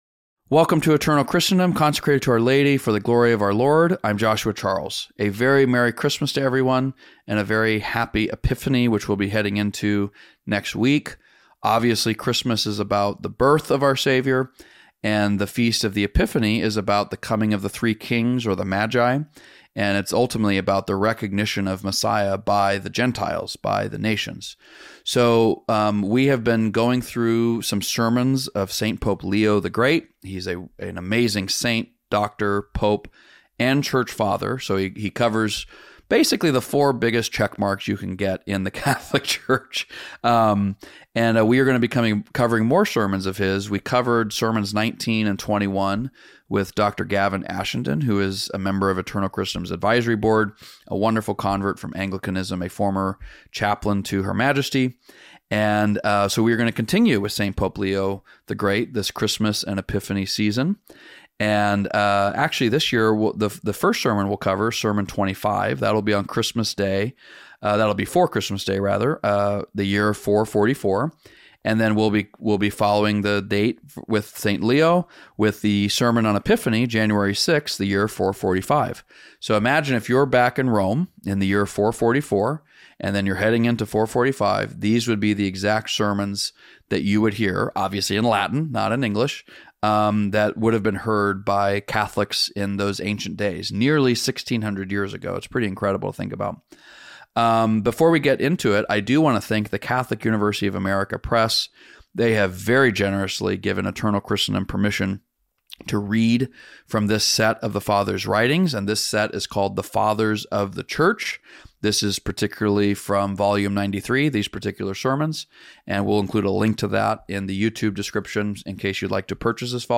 Thank you to Catholic University of America Press for permission to read from their "Fathers of the Church" series.